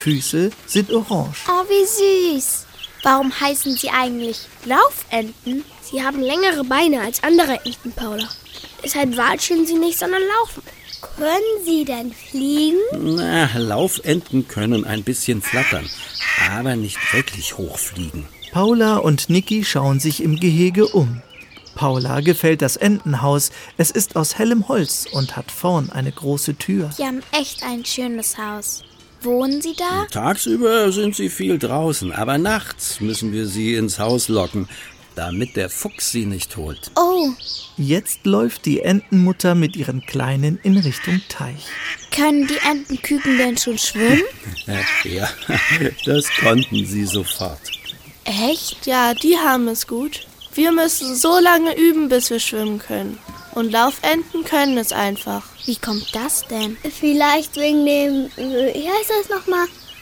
(Hörbuch/Hörspiel - CD)
Hörspiele